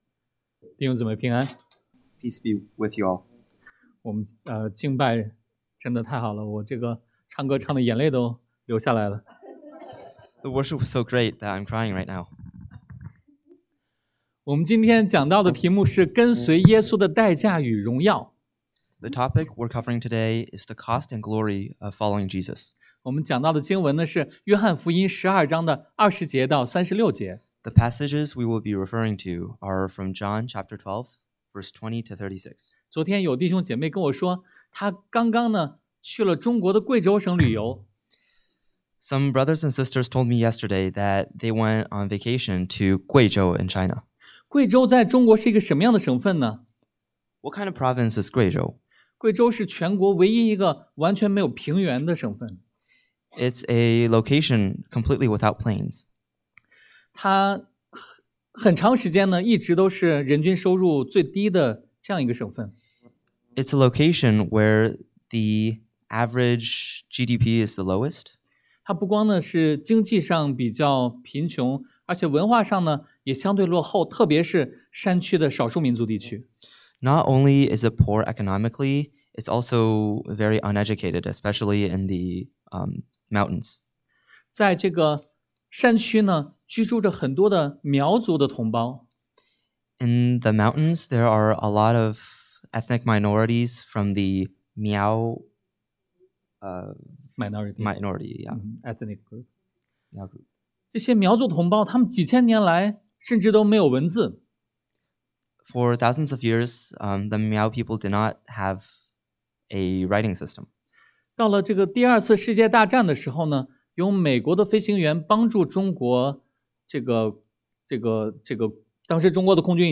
Service Type: Sunday AM 荣耀的时刻已到 The Hour of Glory Has Come 死亡带来生命 Death Brings Life 在光明中行走 Walk in the Light